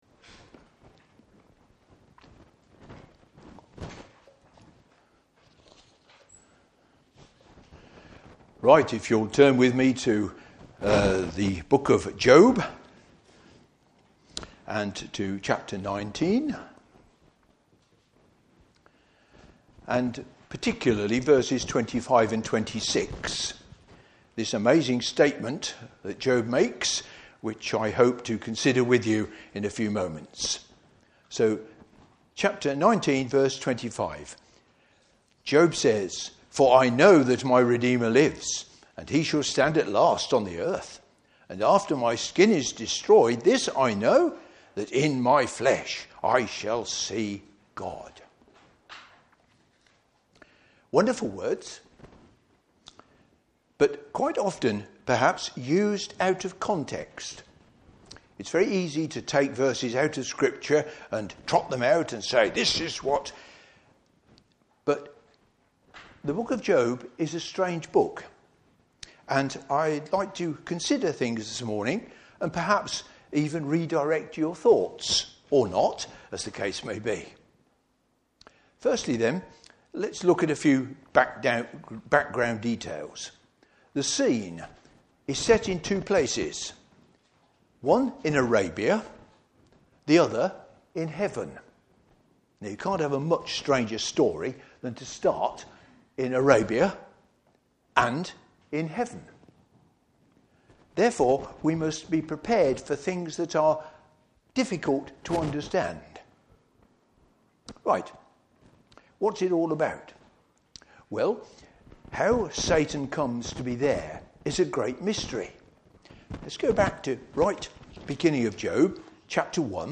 Service Type: Morning Service Bible Text: Job 19:1 – 6, 21 – 27.